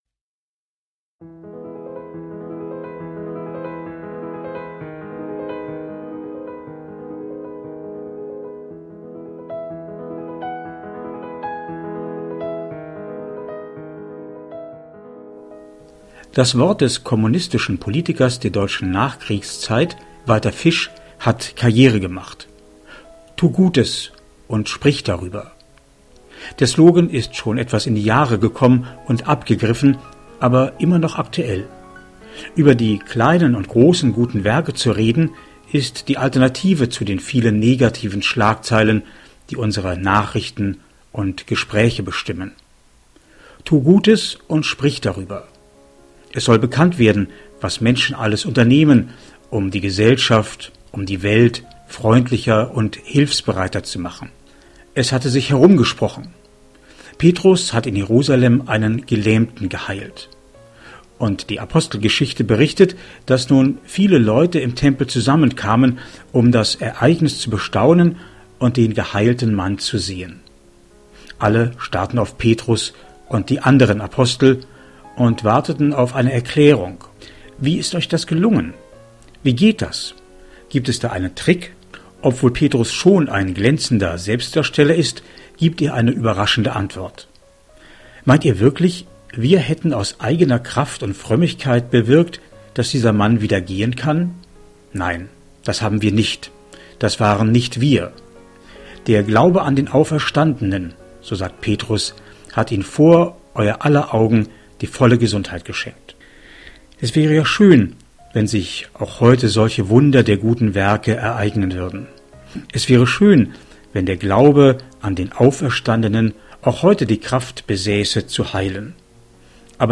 MEDITATION